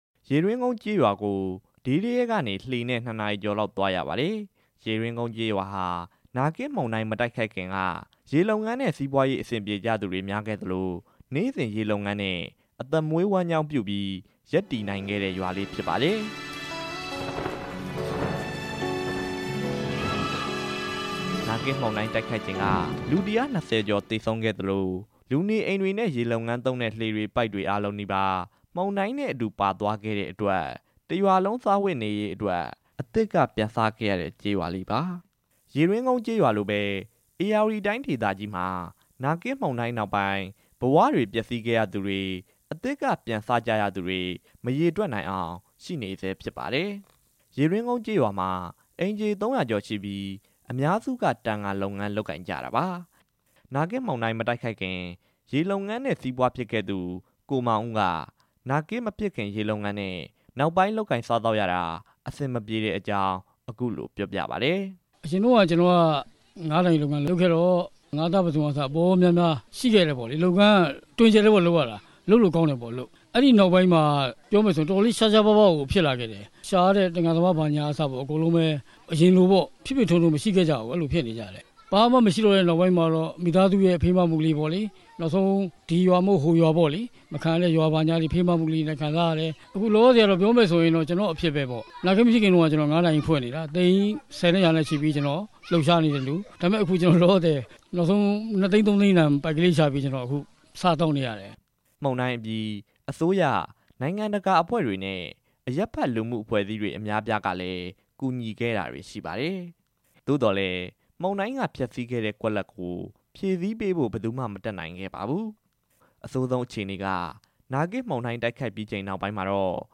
နာဂစ်တိုက်ခတ်ခံခဲ့ရတဲ့ ဒေသအကြောင်း စုစည်းတင်ပြချက်